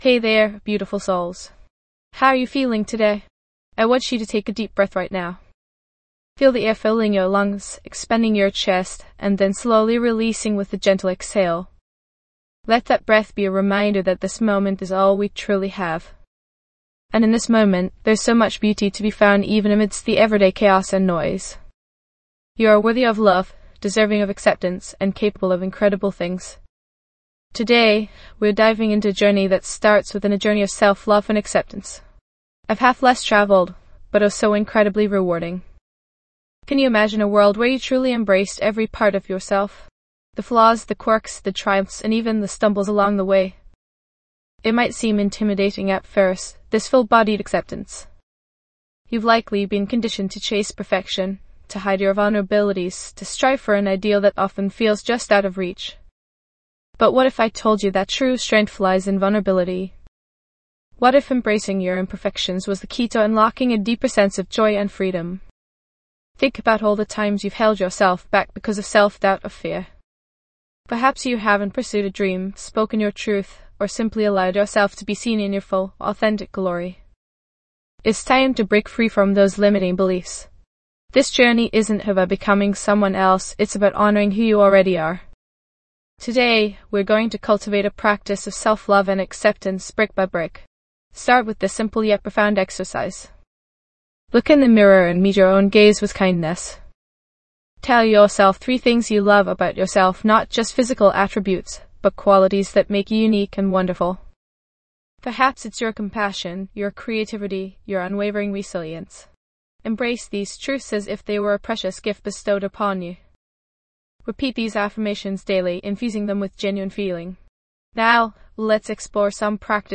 In this episode of The Still Space:. Daily Meditation, discover powerful techniques to nurture self-compassion, embrace your authenticity, and let go of self-judgment. Experience a guided meditation designed to help yo…